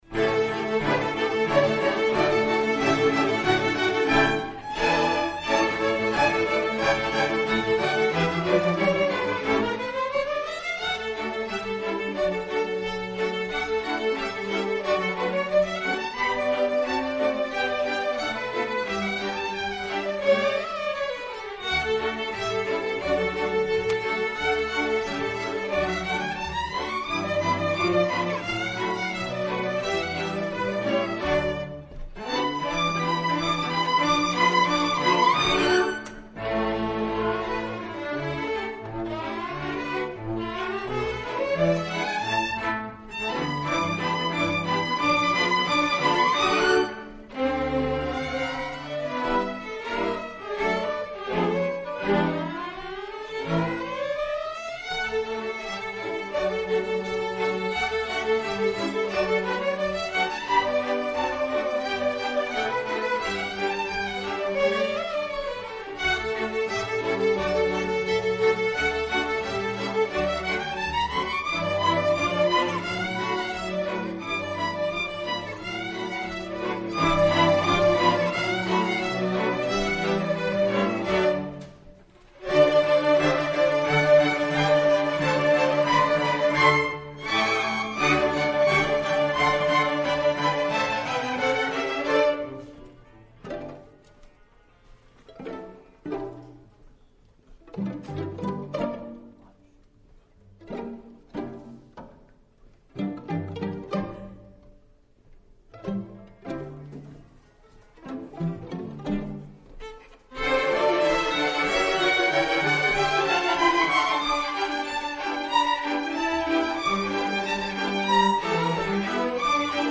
Queen City Community Orchestra
Fall 2018 Concert